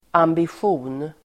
Uttal: [ambisj'o:n]